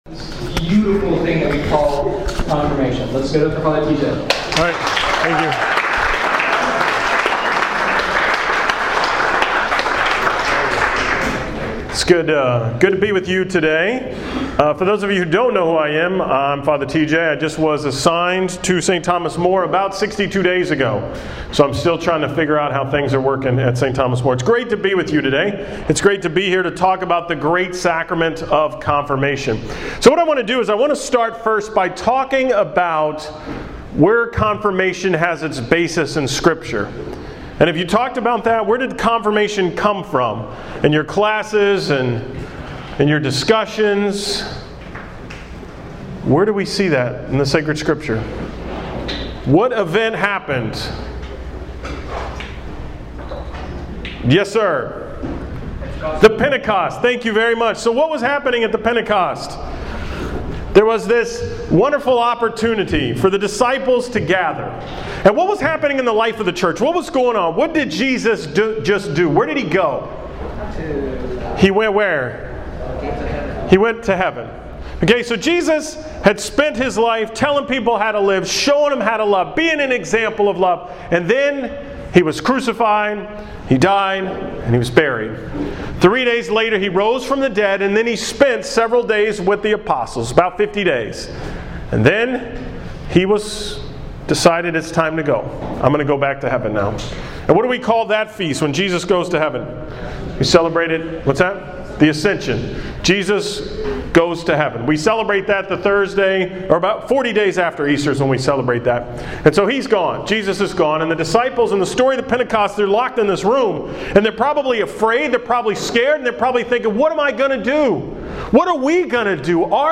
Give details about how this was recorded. From the Confirmation retreat at St. Thomas More on 8-31-13